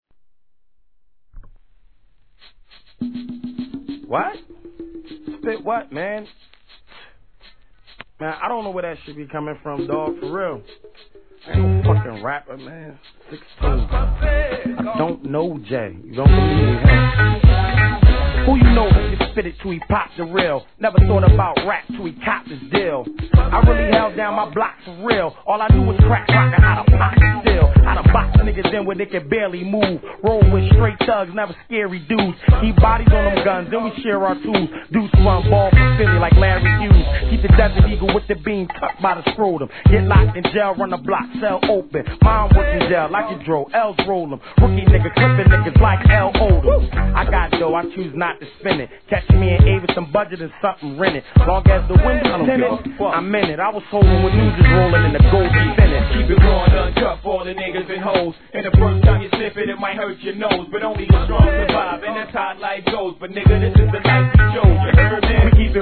HIP HOP/R&B
キラキラした上音、柔らかいメロディーライン、聴く者を優しい気持ちにさせてくれる....そんなメロ〜ヒップホップです♪